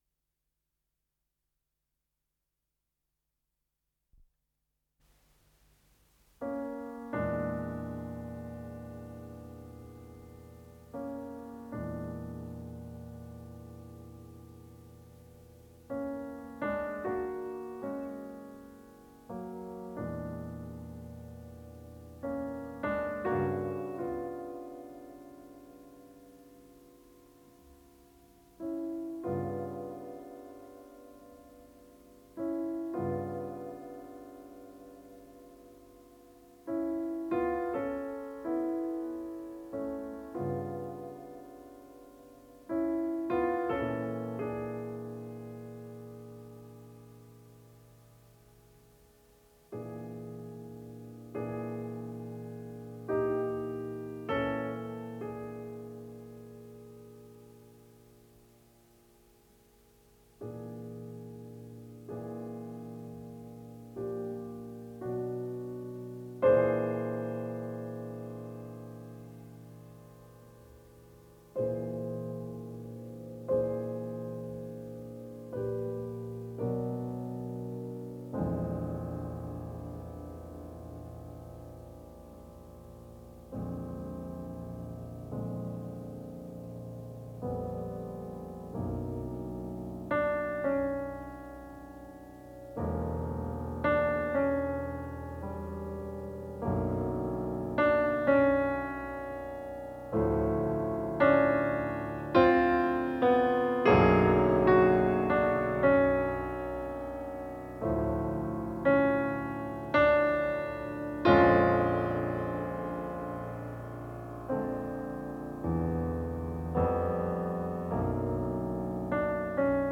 с профессиональной магнитной ленты
ПодзаголовокПоэма для фортепиано, 1914г.
ВариантДубль стерео